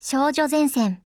贡献 ） 协议：Copyright，其他分类： 分类:少女前线:SP9 、 分类:语音 您不可以覆盖此文件。
SP9_TITLECALL_JP.wav